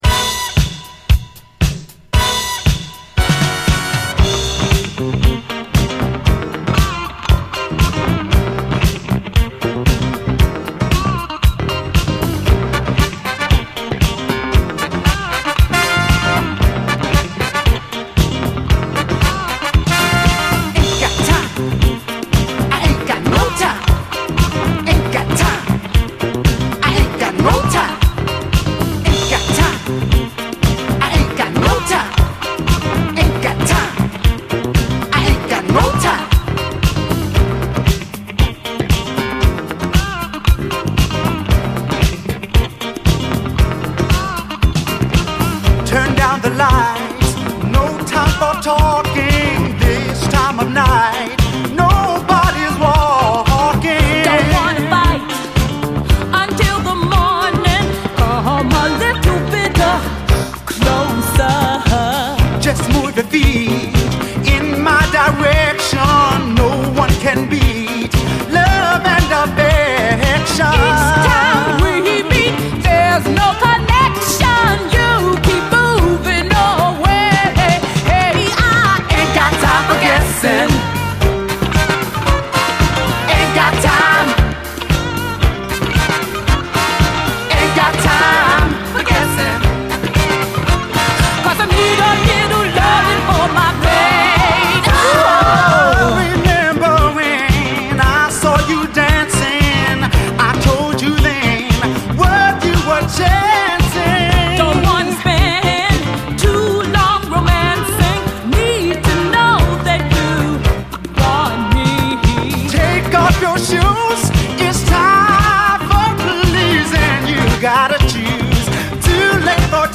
SOUL, 70's～ SOUL, DISCO
ブギー・モダン・ソウル的なソウルフルな躍動感と華やかさのグレイト・ディスコ・ブギー！